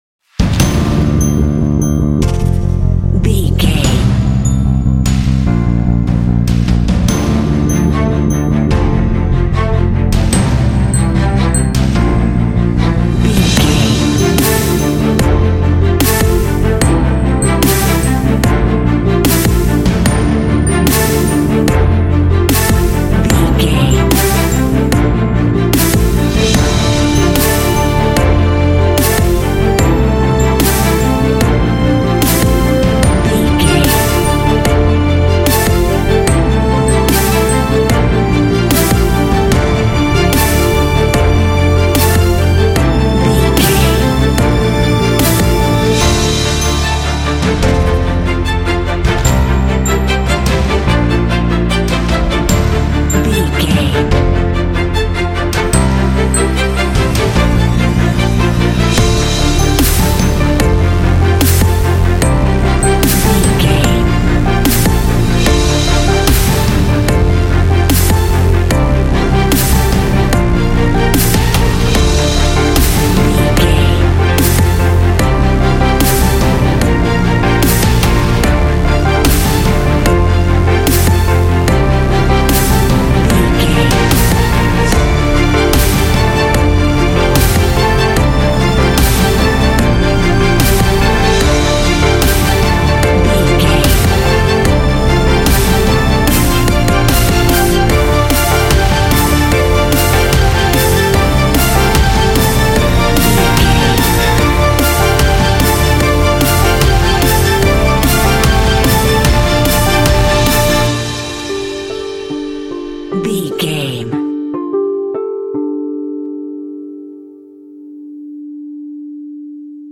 Epic / Action
In-crescendo
Uplifting
Aeolian/Minor
driving
energetic
drum machine
strings
piano
percussion
cinematic
orchestral
contemporary underscore